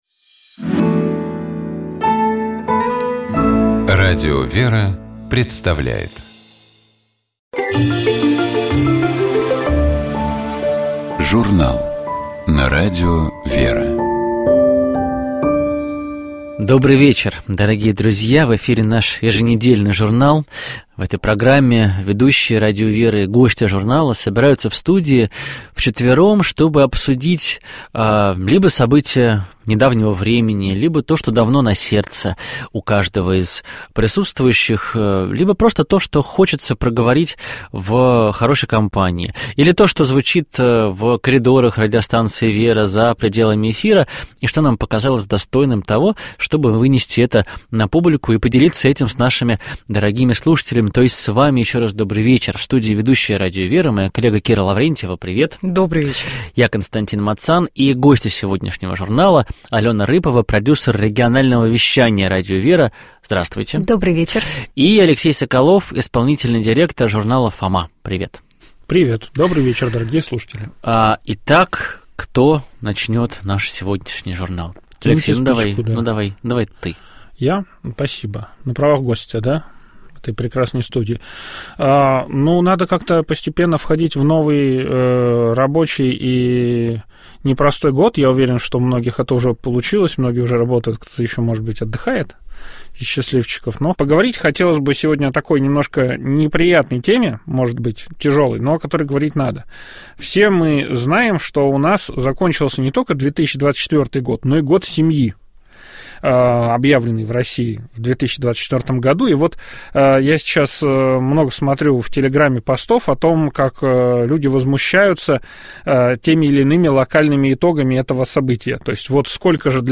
Фрагмент из неё прозвучал в начале программы. Премьера фильма состоялась в 2020-м году в рамках 42-го Московского Международного кинофестиваля.